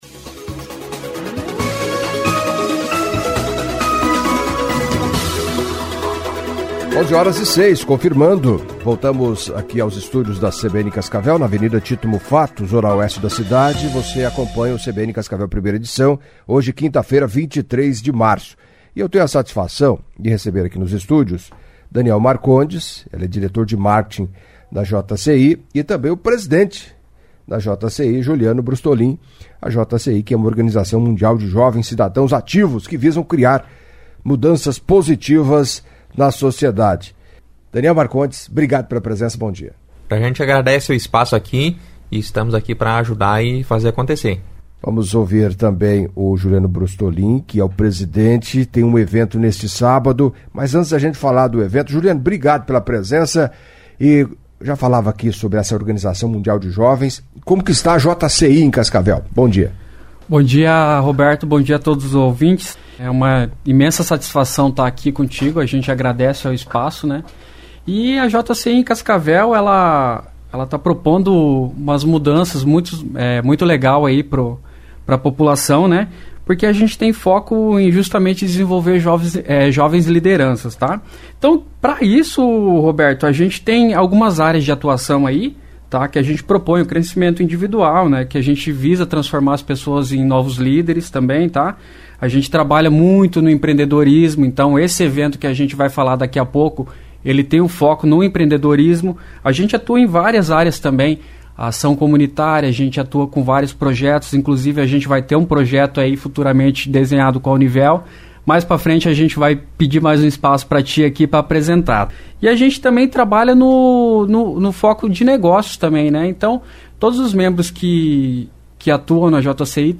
Em entevista à CBN Cascavel nesta quinta-feira